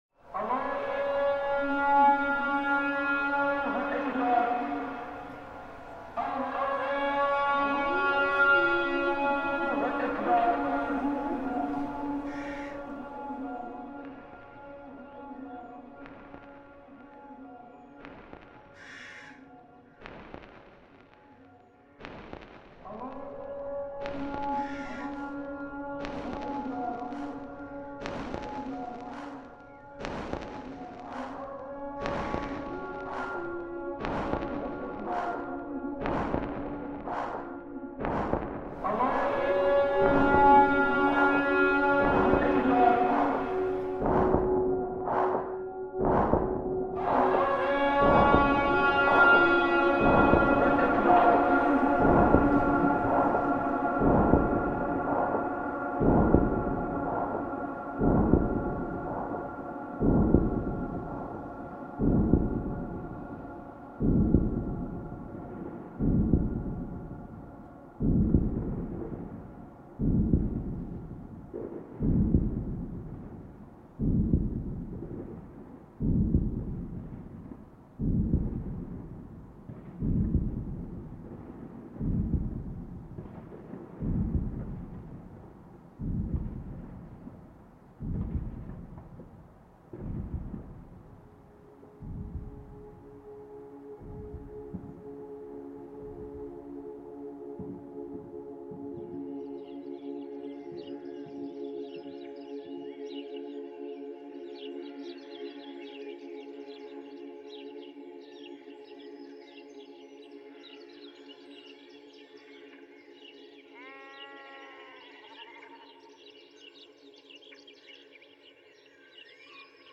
Call to prayer in Tunisia reimagined